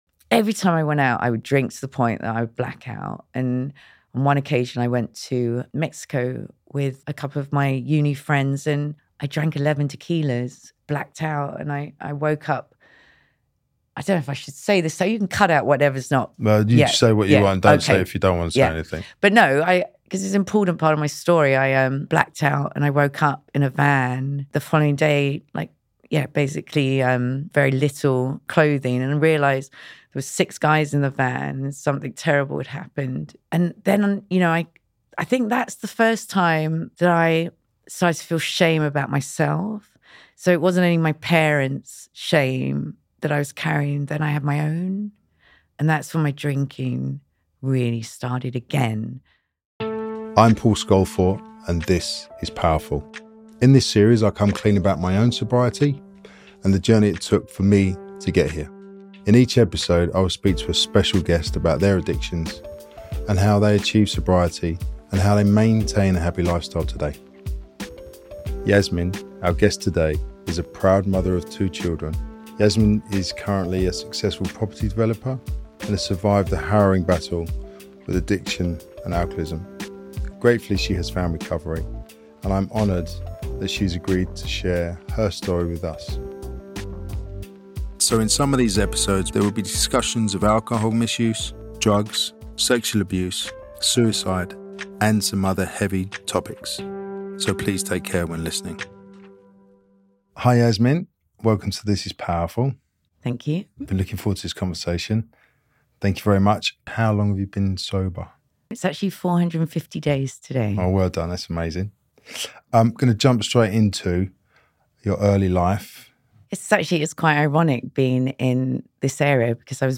This is a conversation not to be missed.